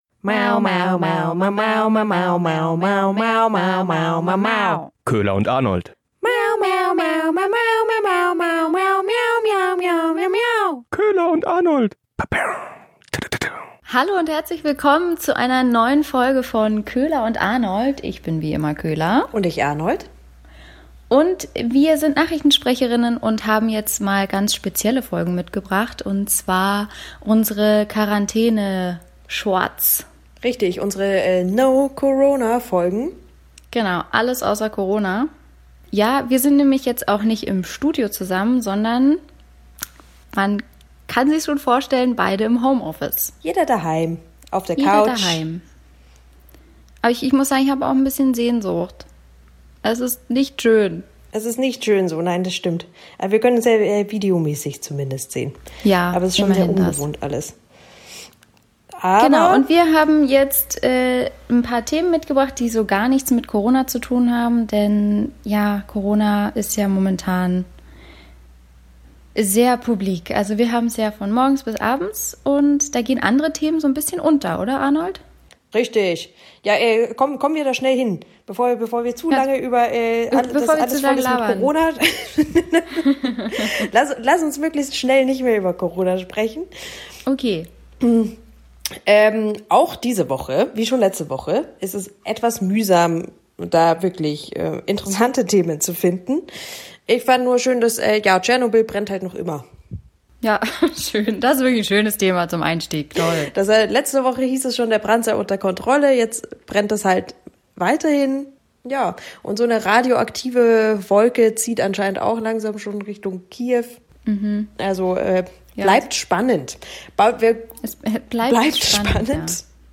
Wir melden uns wieder aus dem Home Office!